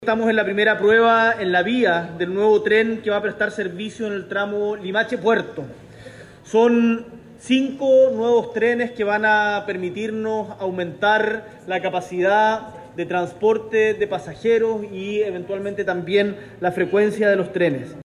Así lo confirmó el presidente, quien valoró la llegada de los nuevos móviles y los beneficios que tendrá para la comunidad.